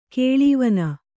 ♪ kēḷī vana